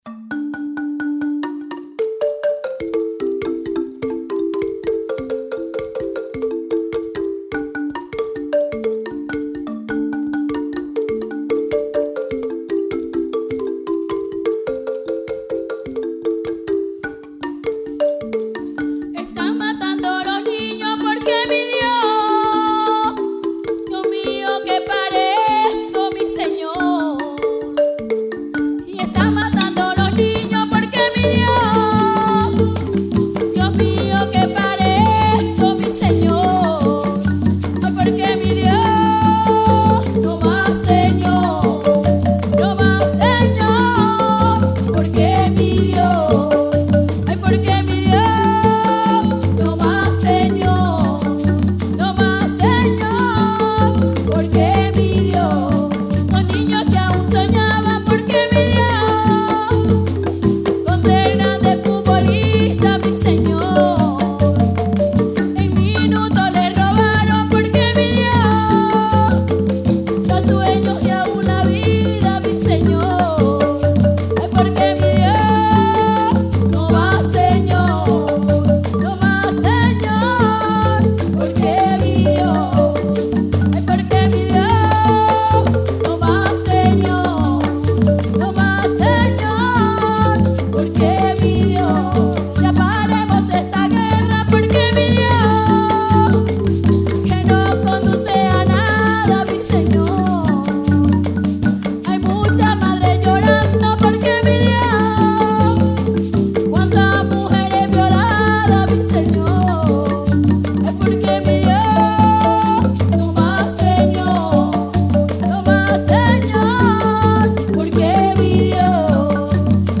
Canción
voz.
cununo, bordón, marimba, requinto, bombo y guasá.
coros.